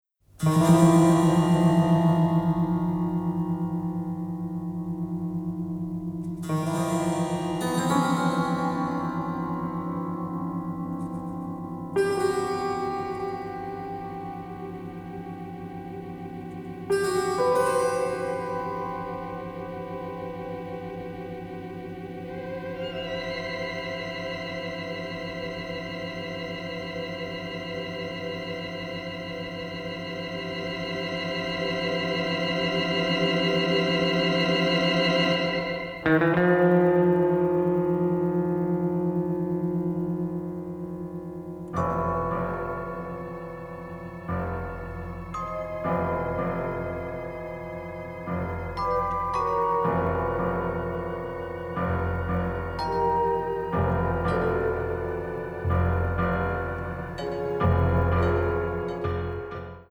psychedelic cult classics
largely characterized by a baroque feel
children’s vocals, lullabies and a cembalo